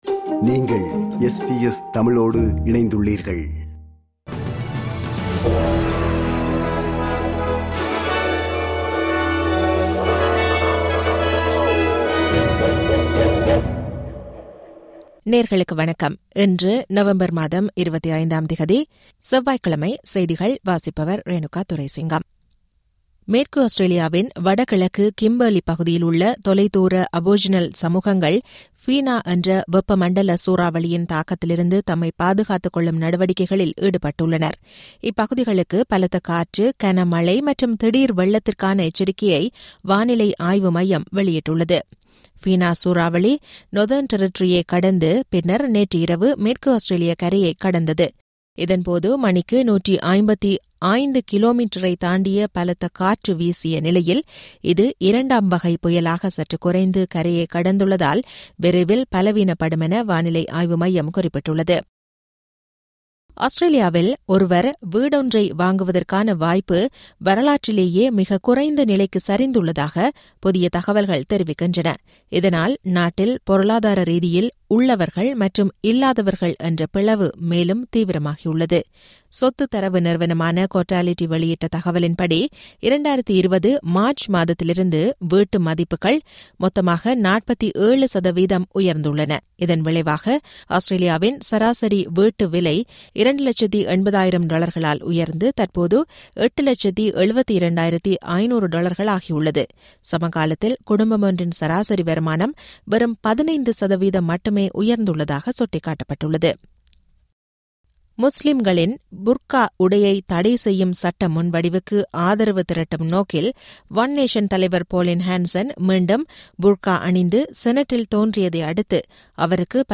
இன்றைய செய்திகள்: 25 நவம்பர் 2025 செவ்வாய்க்கிழமை
SBS தமிழ் ஒலிபரப்பின் இன்றைய (செவ்வாய்க்கிழமை 25/11/2025) செய்திகள்.